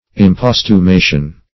Imposthumation \Im*post`hu*ma"tion\, n.